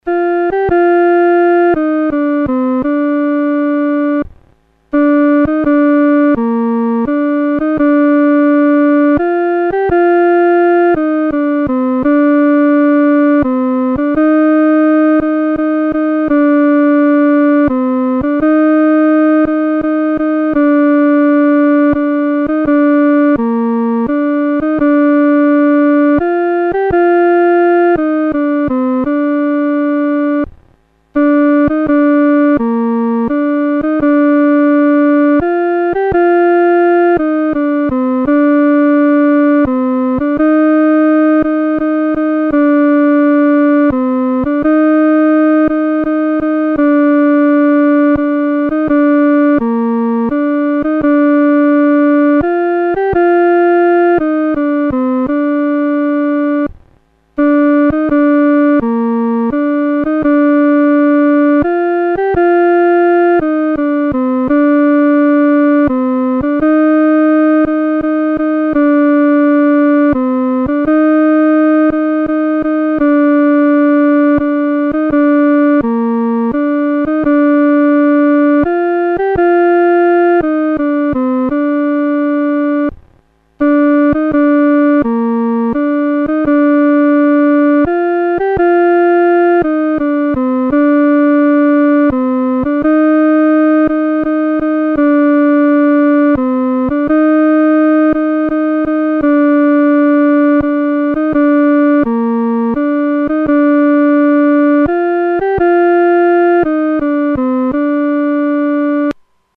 独奏（第二声）
万古磐石-独奏（第二声）.mp3